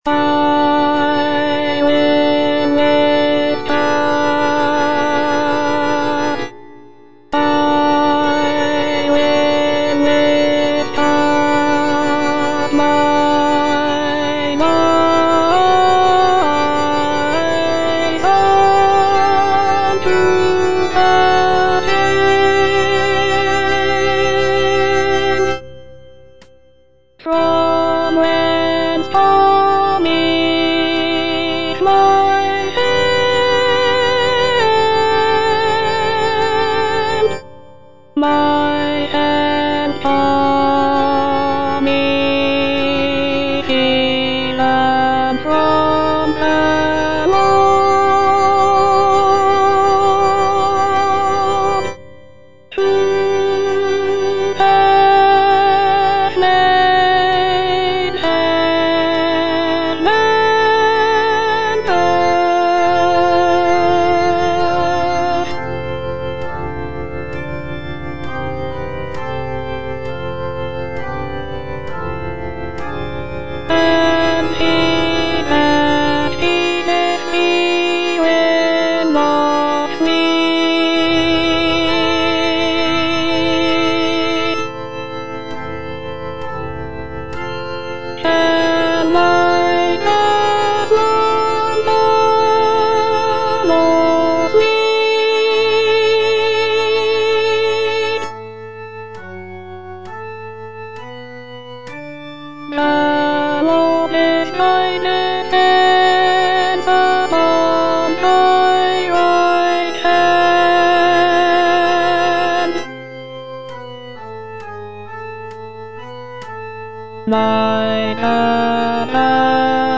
Alto II (Voice with metronome)